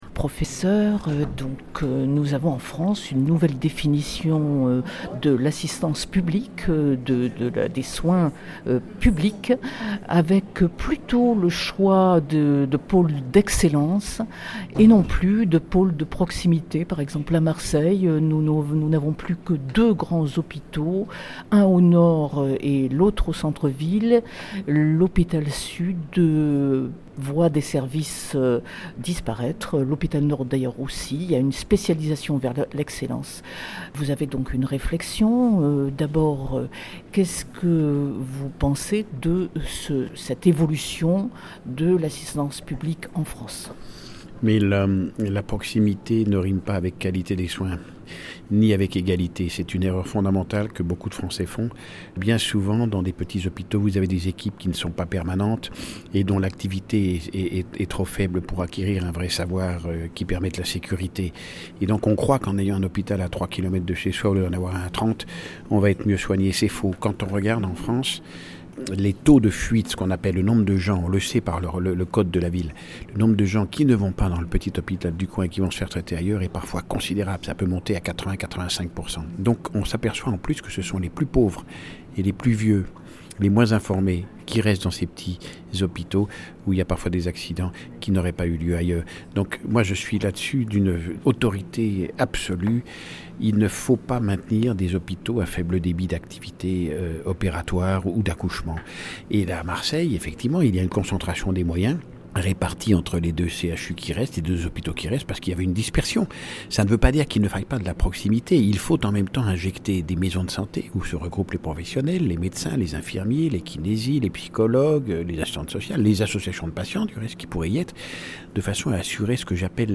16e édition du forum des entrepreneurs : Entretien avec le Pr. Guy Vallancien - Destimed